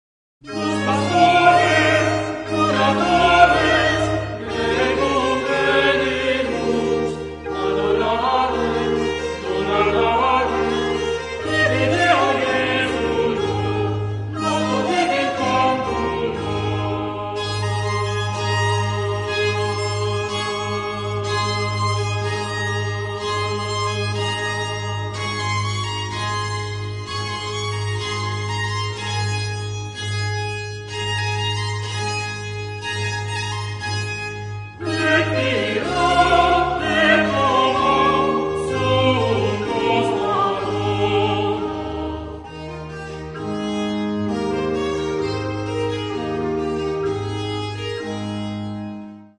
He composed most of his spiritual works for the local choir; his style was in transition from baroque and classical music. His melody and instrumentation is heavily influenced by North Slovakian traditional musicians.